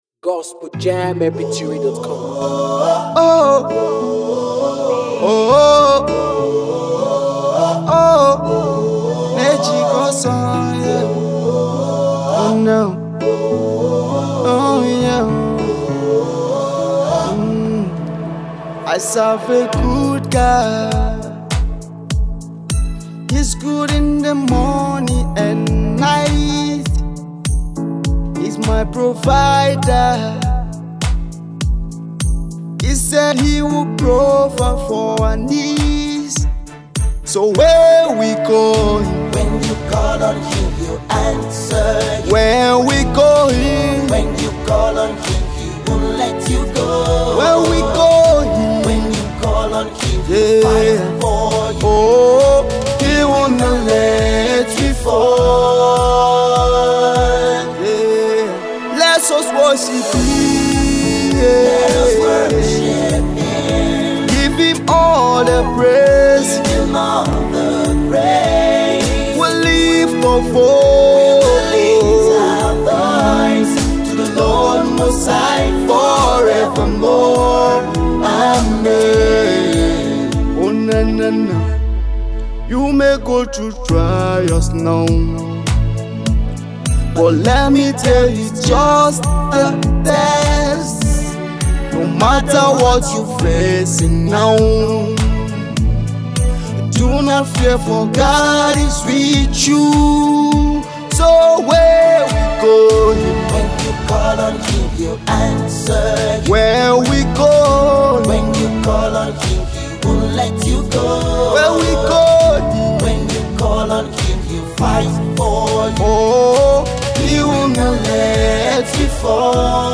A powerful worship song.